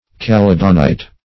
Search Result for " caledonite" : The Collaborative International Dictionary of English v.0.48: Caledonite \Ca*led"o*nite\, n. (Min.) A hydrous sulphate of copper and lead, found in some parts of Caledonia or Scotland.